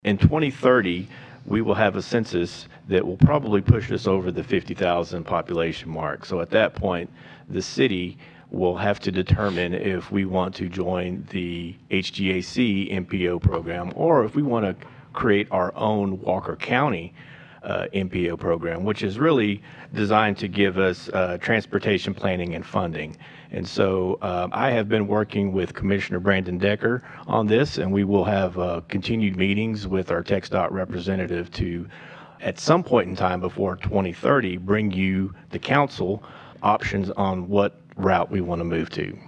Interim City Manager Sam Maisal informed councilmembers.